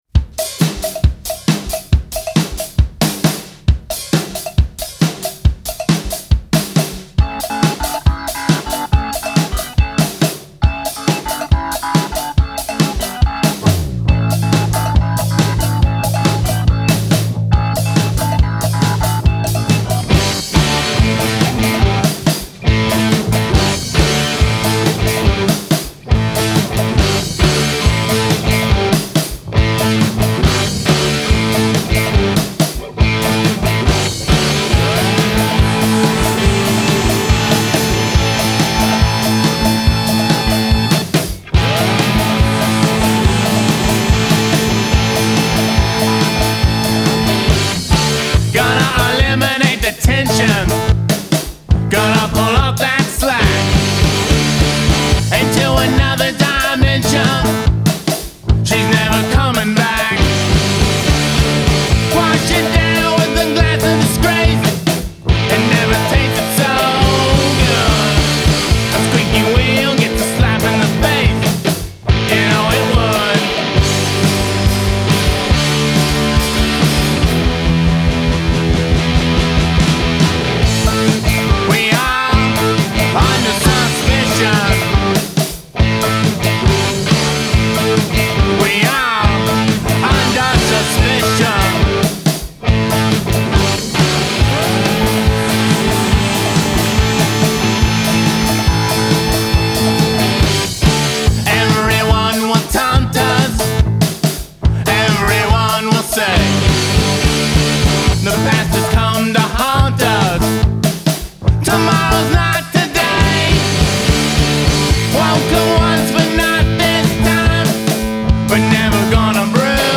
Guitar and Vocals
Bass
Drums
Keyboards